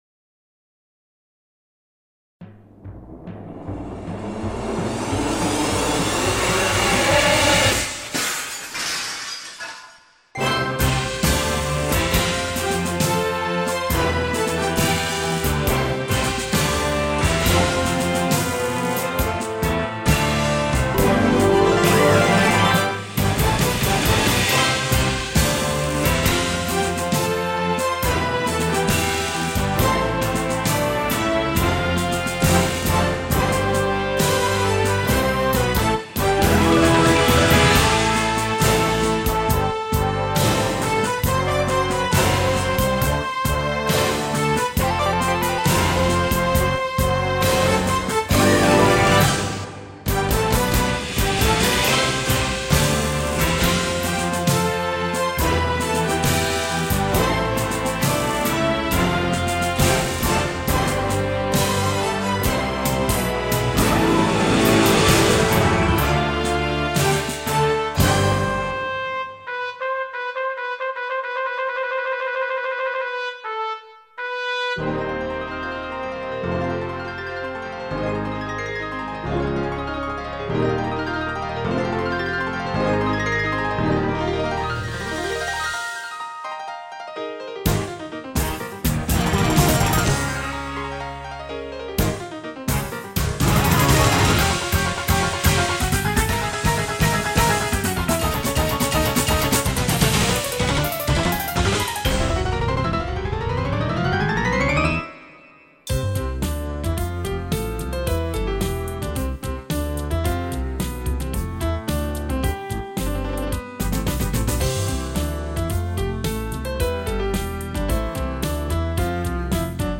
They were entirely created using midi.